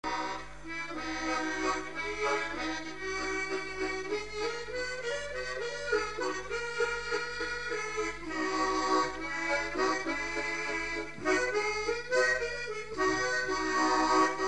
Mémoires et Patrimoines vivants - RaddO est une base de données d'archives iconographiques et sonores.
Valse
danse : valse
Pièce musicale inédite